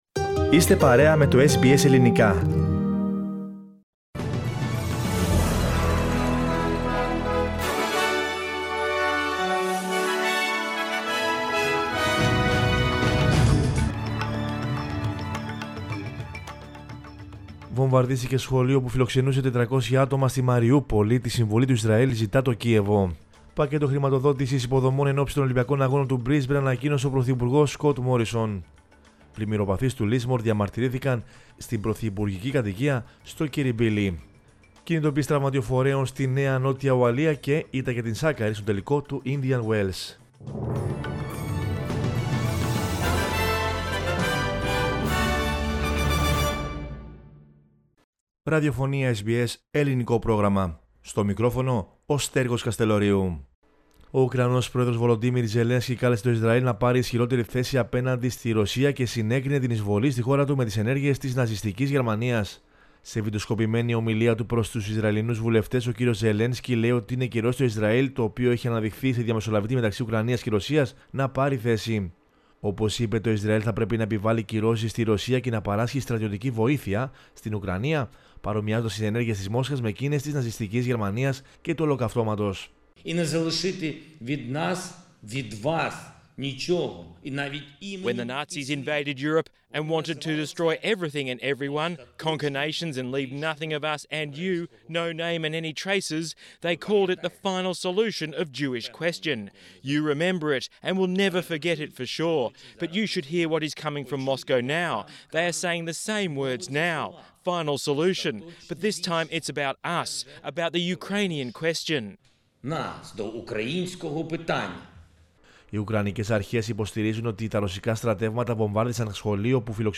News in Greek from Australia, Greece, Cyprus and the world is the news bulletin of Monday 21 March 2022.